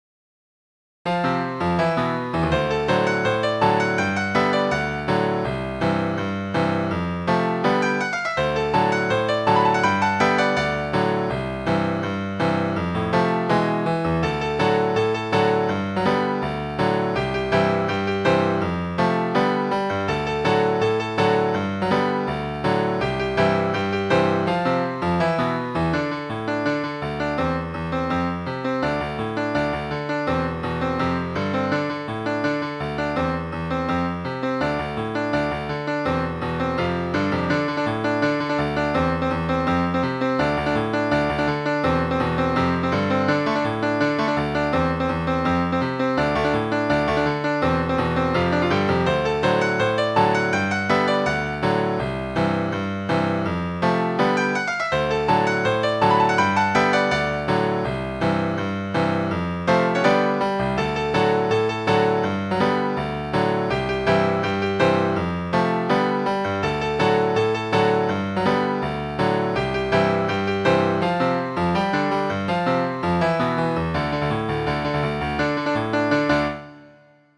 A piano piece with a touch of whimsy.